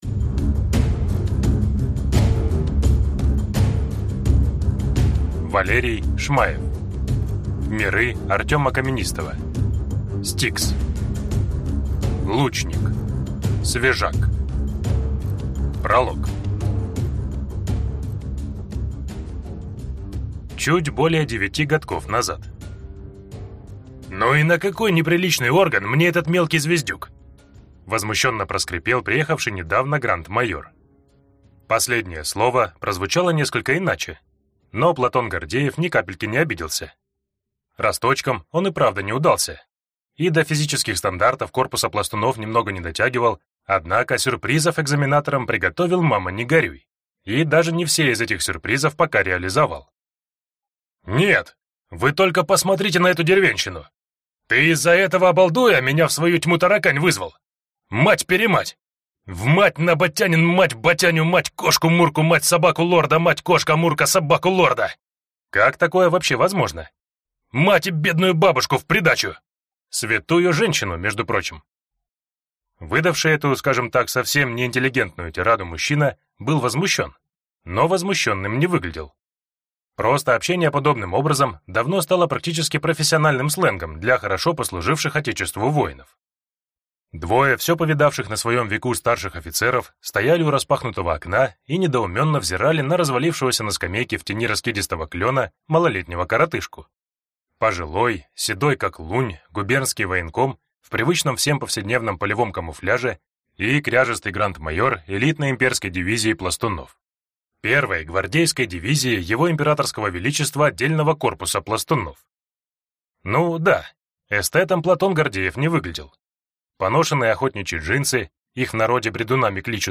Аудиокнига Лучник (свежак) | Библиотека аудиокниг